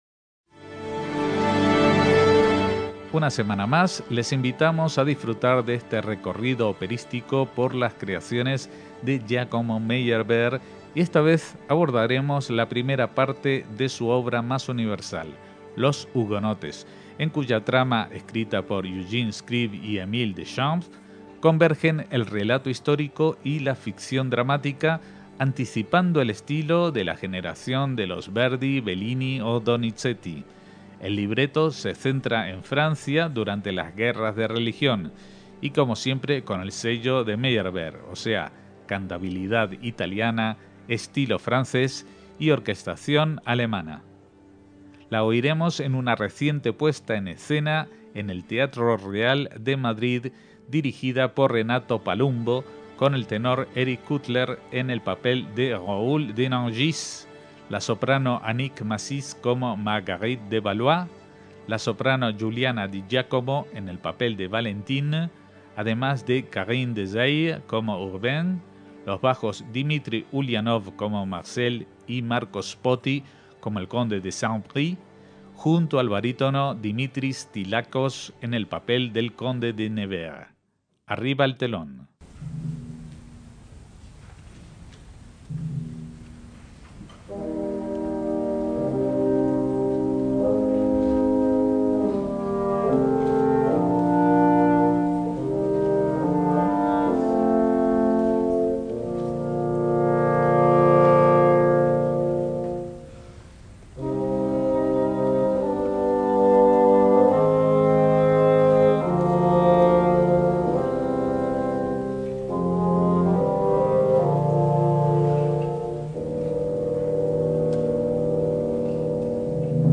grand opéra en cinco actos
orquesta y coro
voces solistas